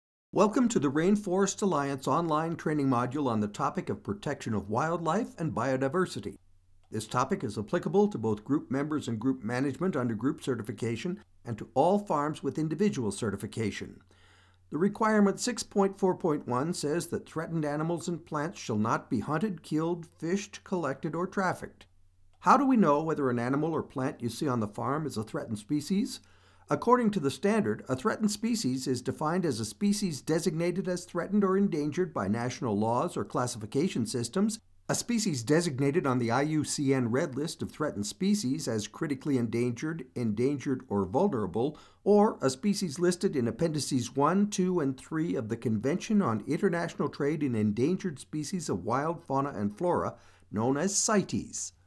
Never any Artificial Voices used, unlike other sites.
Male
English (North American)
Adult (30-50), Older Sound (50+)
E-Learning
Male Voice Over Talent